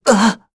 Lucias-Vox_Damage_02_kr.wav